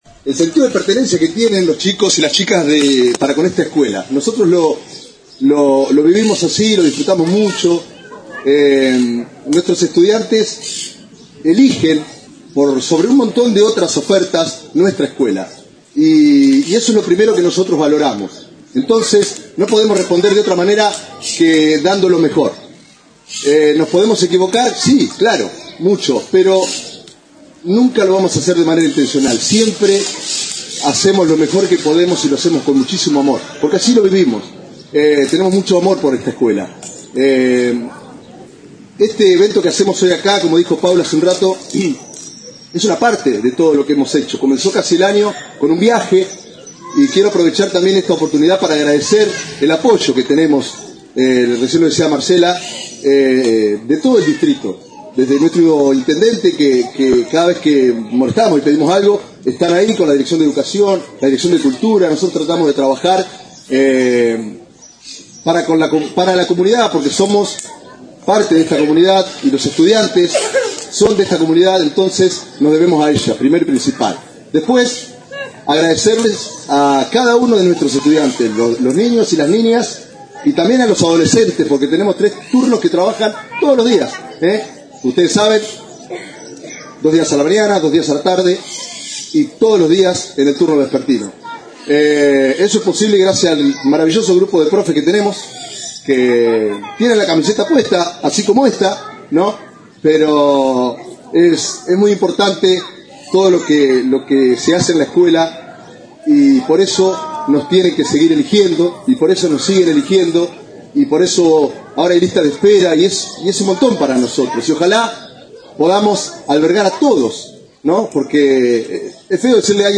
La Escuela de Estética cerró el año con una interesante muestra